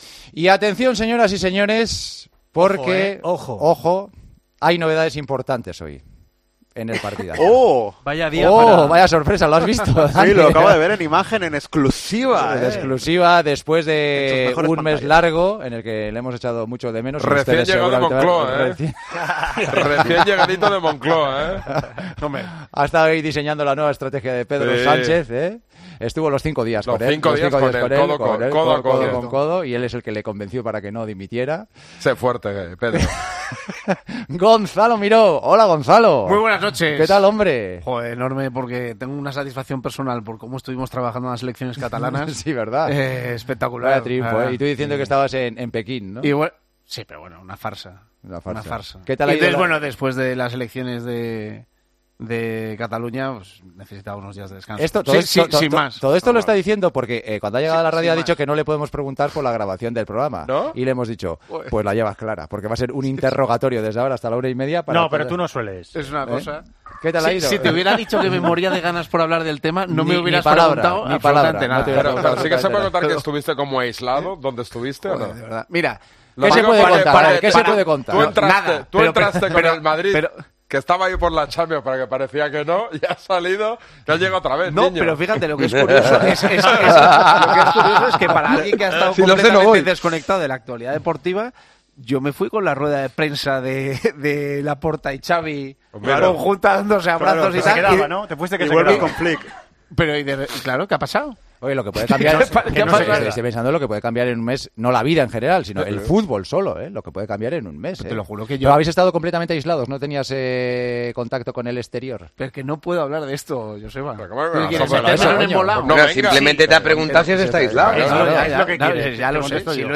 Gonzalo Miró ha reaparecido por los micrófonos de El Partidazo y se ha mostrado algo desubicado con la cantidad de cosas que han ocurrido desde el inicio de su aventura y en la que ha estado incomunicado durante toda la grabación: "Es curioso, pero yo he estado desconectado de la actualidad deportiva y me fui con la rueda de prensa en la que Laporta y Xavi se rejuntaron y abrazaron y de repente... ¿Qué ha pasado?", bromeaba el tertuliano.